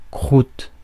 Ääntäminen
Ääntäminen France: IPA: /kʁut/